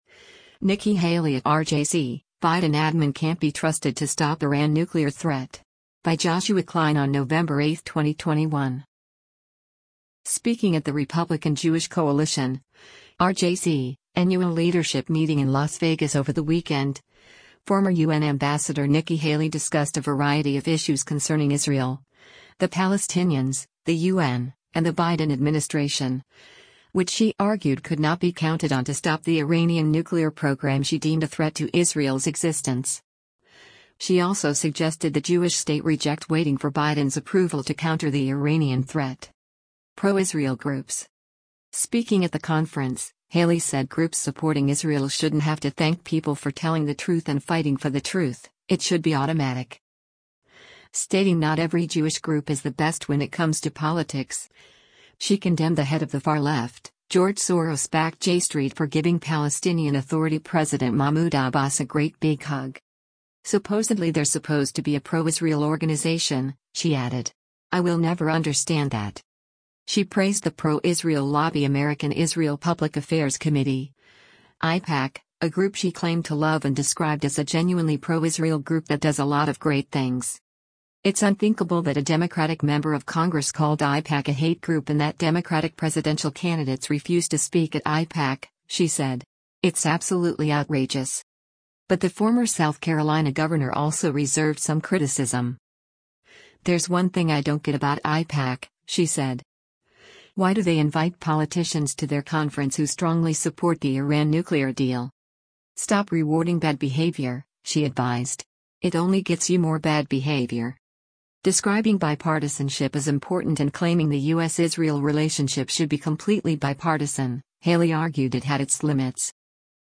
Speaking at the RJC annual leadership meeting in Las Vegas over the weekend, former U.N. ambassador Nikki Haley discussed a variety of issues.